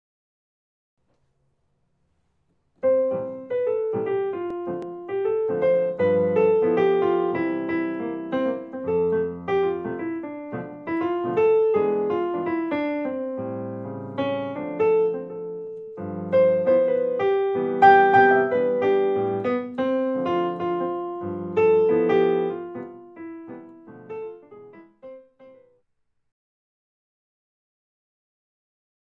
Standards & Contemporary